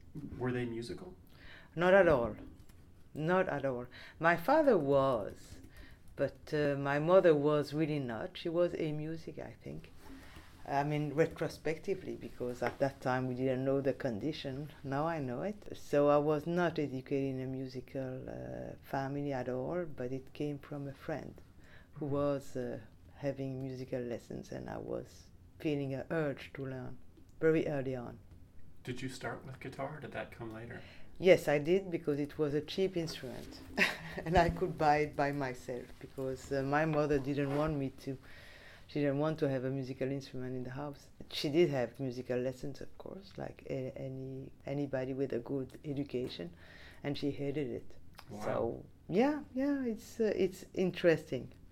Dr. Peretz describes her parents in the following two clips.